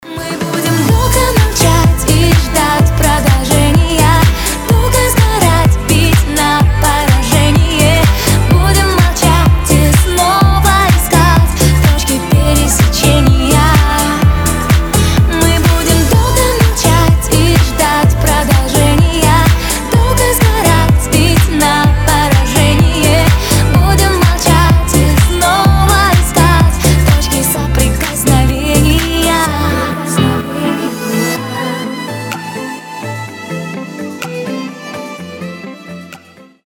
• Качество: 320, Stereo
поп